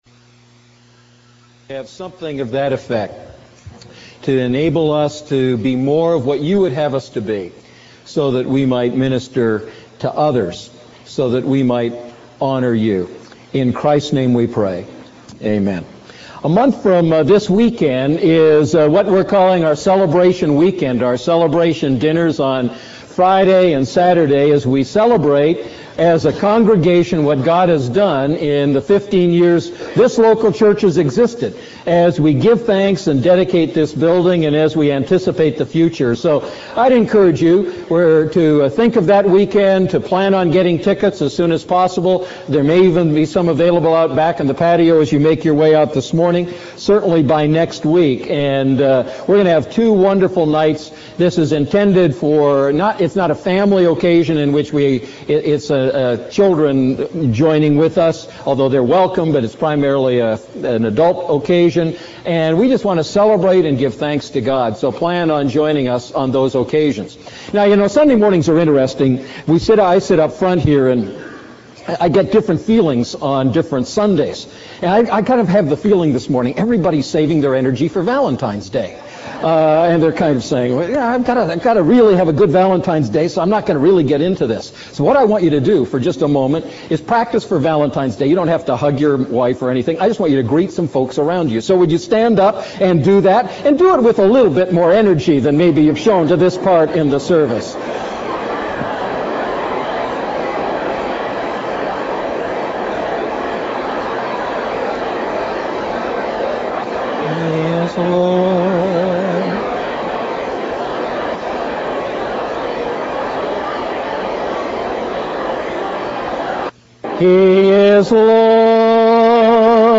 A message from the series "Living Inside Out."